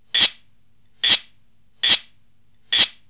4.2.2.1.CẶP KÈ hay SÊNH SỨA
Chẹt: Ví dụ: (469-2a)